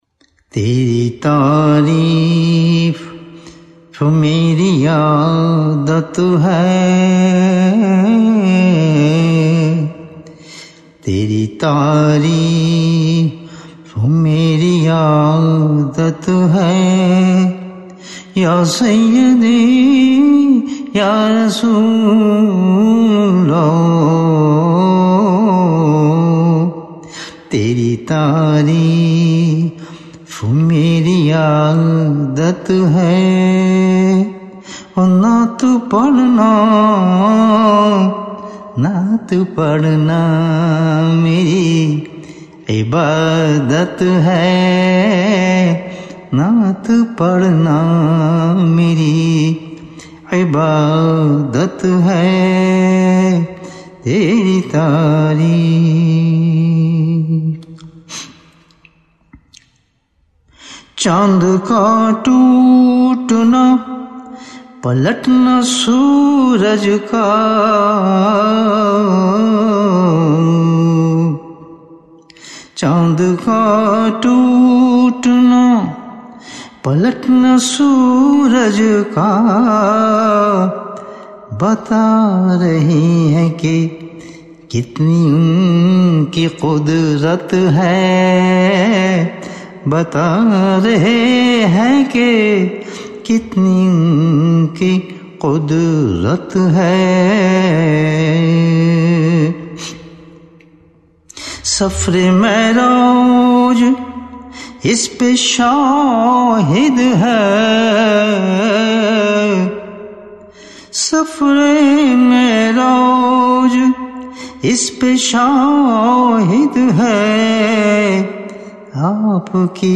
Subhanallah nice naat and heart touching voice
SUBHAN ALLAH What a heart touching Naat.